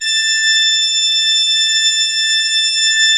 Index of /90_sSampleCDs/Propeller Island - Cathedral Organ/Partition I/MAN.PLENO R